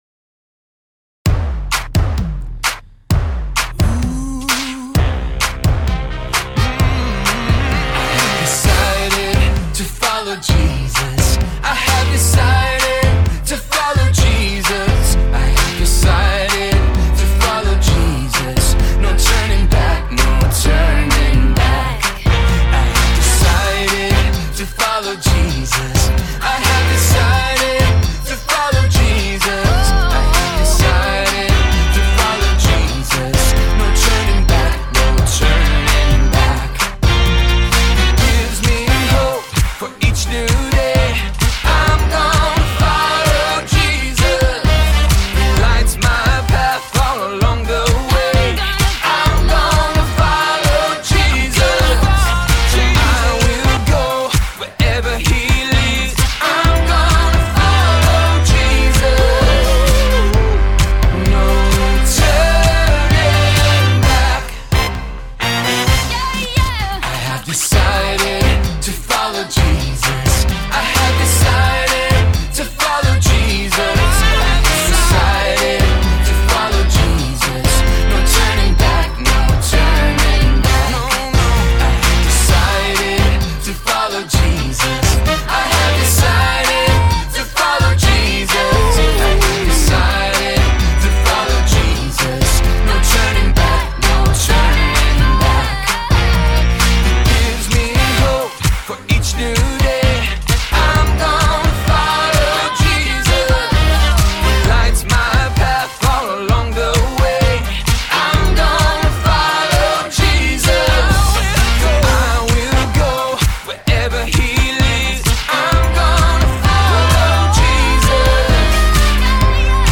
视频里有动作演示，音频里歌会自动重复三遍。